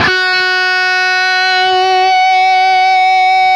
LEAD F#3 CUT.wav